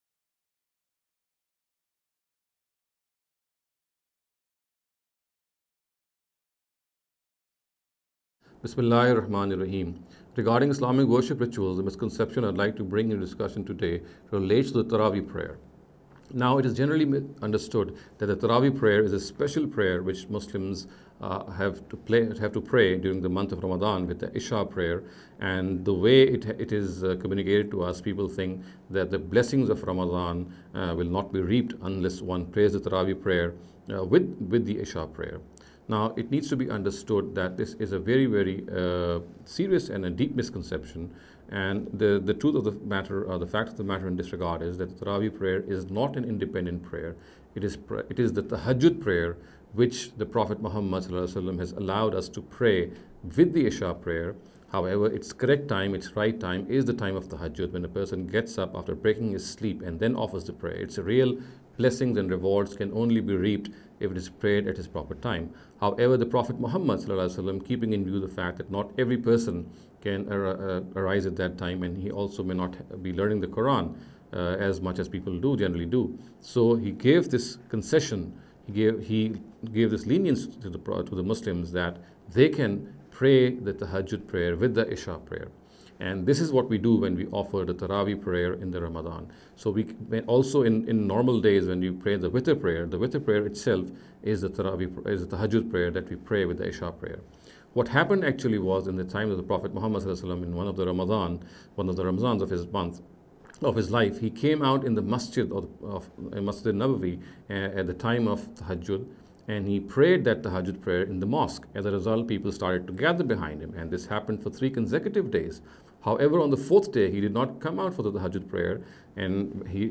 In the series of short talks “Islamic Worship Rituals